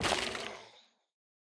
Minecraft Version Minecraft Version latest Latest Release | Latest Snapshot latest / assets / minecraft / sounds / block / sculk / break2.ogg Compare With Compare With Latest Release | Latest Snapshot